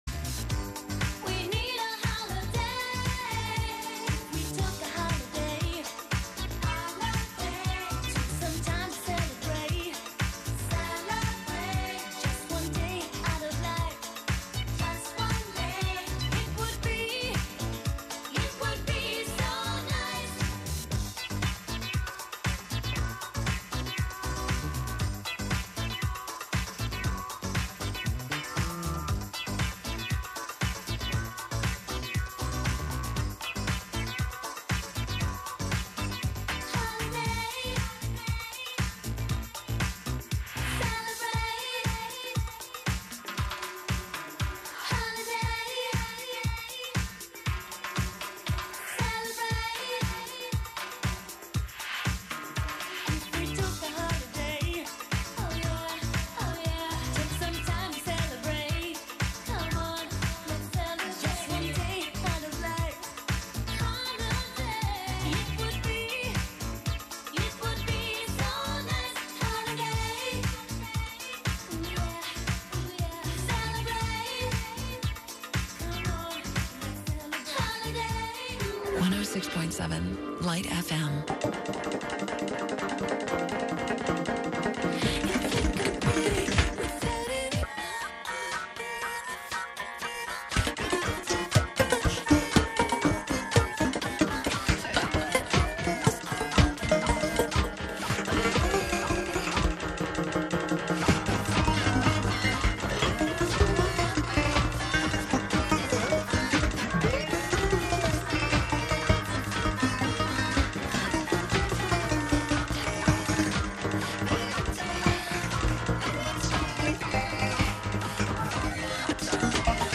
11am Live from Brooklyn, New York
instant techno